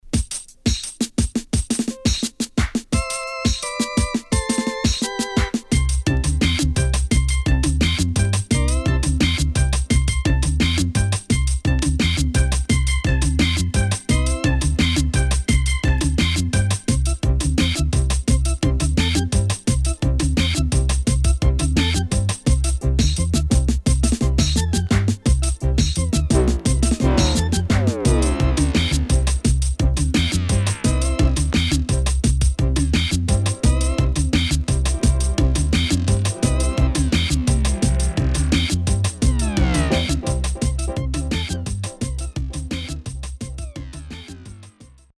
Good Female Vocal
SIDE A:少しノイズ入ります。